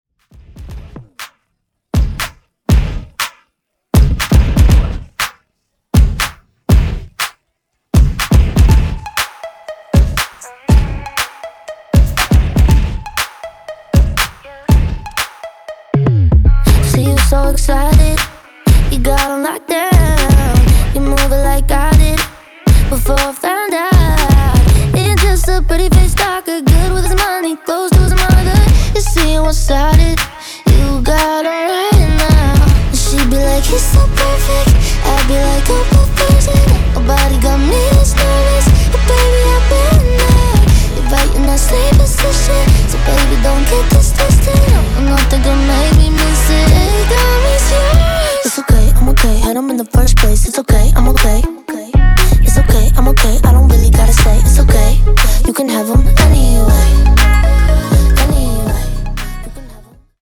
Genres: RE-DRUM , TOP40 Version: Clean BPM: 120 Time